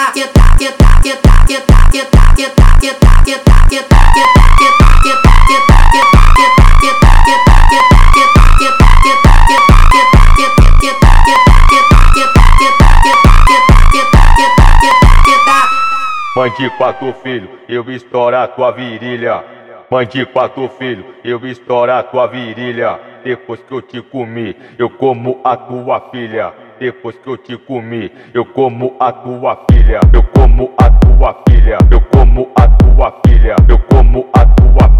Жанр: Фанк